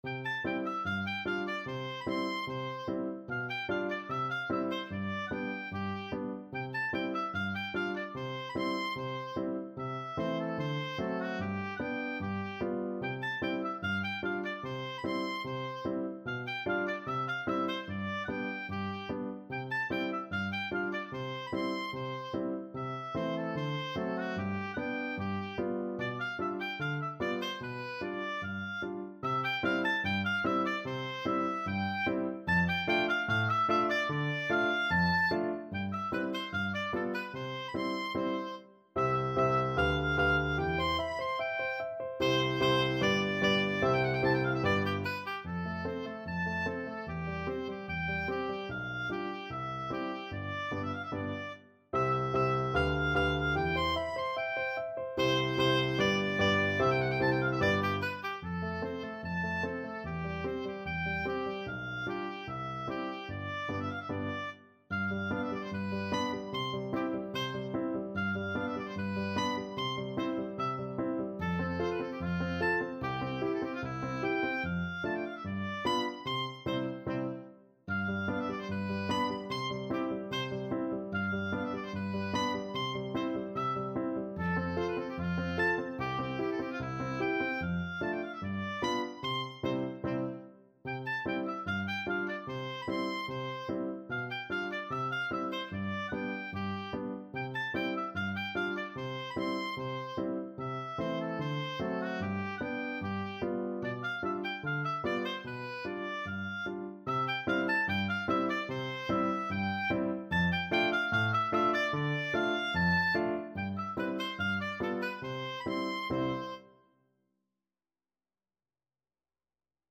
2/2 (View more 2/2 Music)
Allegretto = 74
Oboe  (View more Intermediate Oboe Music)
Classical (View more Classical Oboe Music)